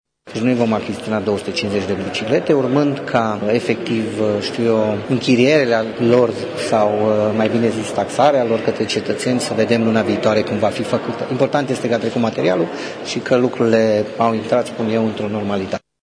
Viceprimarul Claudiu Maior a explicat că important este că proiectul a fost votat şi că toate celelalte probleme vor fi rezolvate printr-un regulament ce va fi supus votului Consiliului Local: